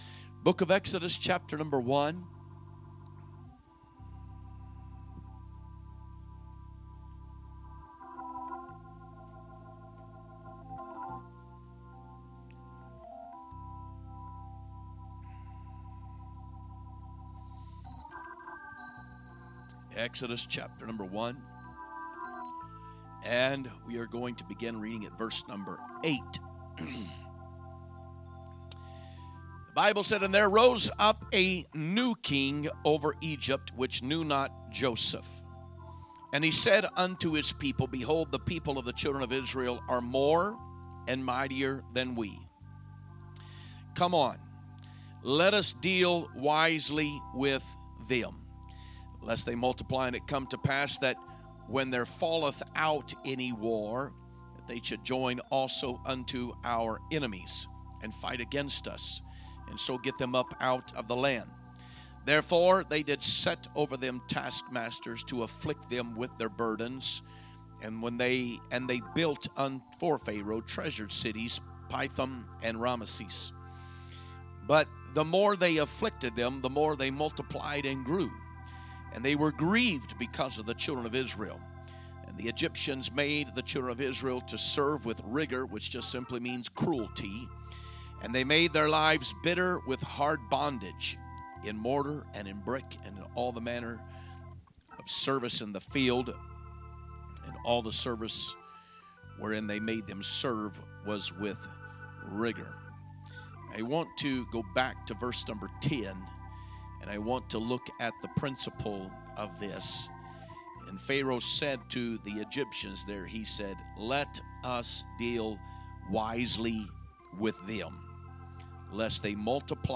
Sunday Morning Message
2025 Sermons